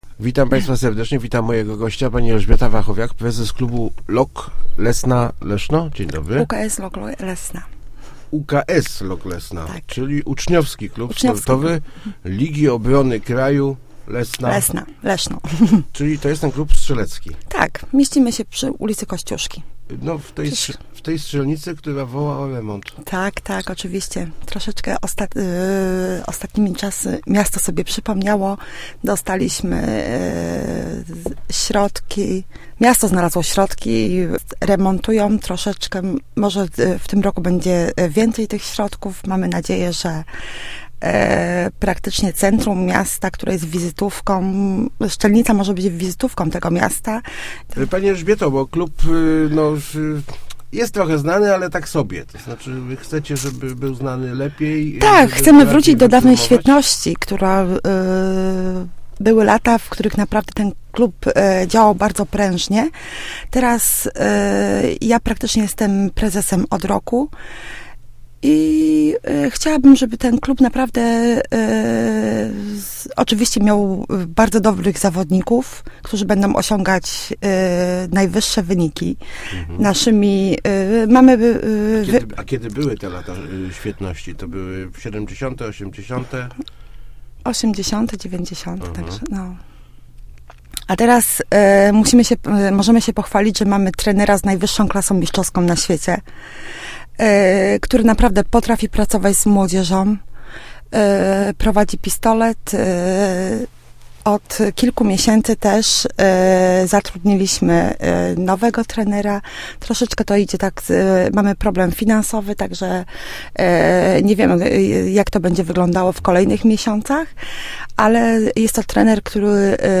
Start arrow Rozmowy Elki arrow Strzelcy z olimpijskimi ambicjami